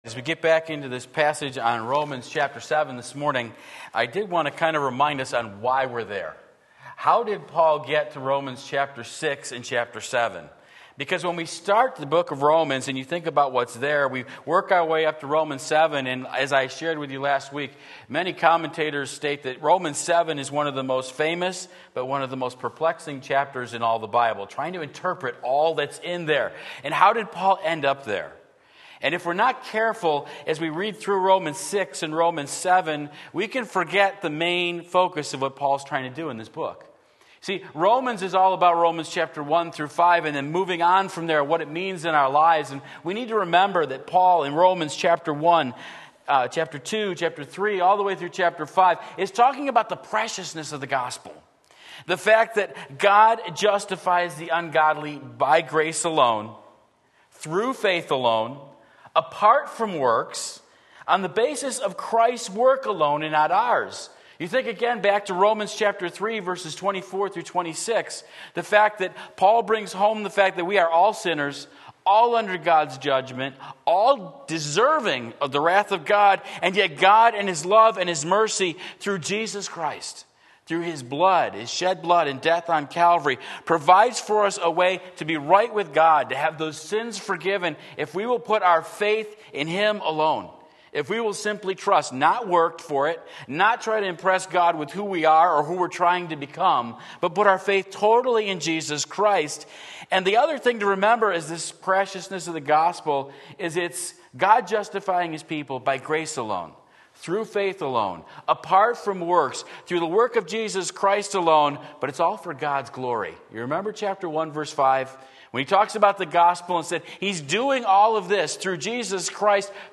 Sermon Link
The Law Promised Life but Sin Delivered Death Romans 7:7-13 Sunday Morning Service